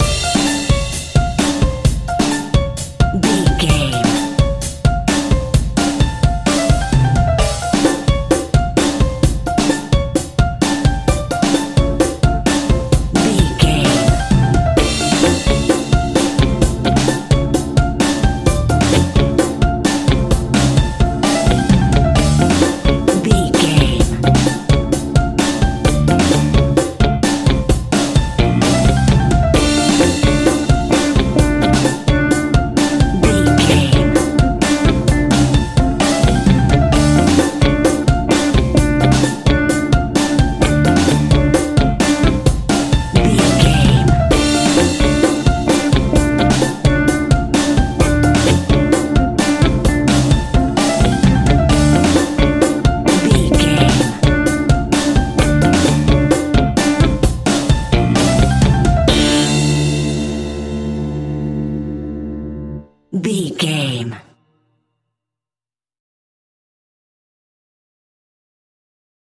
Aeolian/Minor
flamenco
maracas
percussion spanish guitar